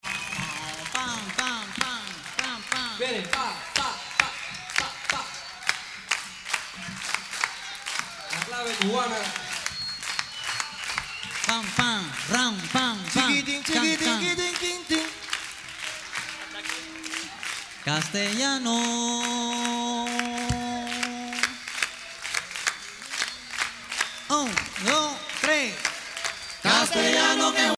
Polyphonie de chanteurs africains
Pièce musicale inédite